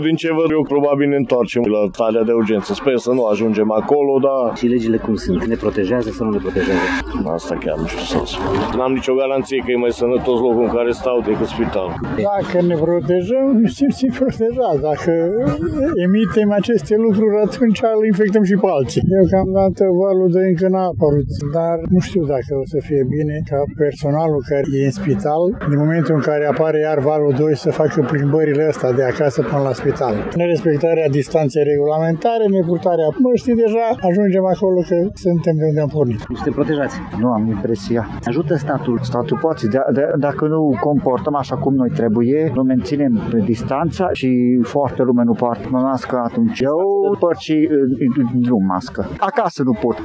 Dincolo de măsurile luate de autorități, târgumureșenii cred că această pendemie de coronavirus va fi oprită doar dacă fiecare persoană respectă măsurile simple de protecție- purtarea măștii, păstrarea distanței față de alte persoane și dezinfectarea mâinilor: